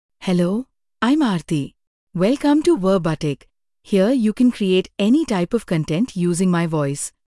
FemaleEnglish (India)
AartiFemale English AI voice
Aarti is a female AI voice for English (India).
Voice sample
Female
Aarti delivers clear pronunciation with authentic India English intonation, making your content sound professionally produced.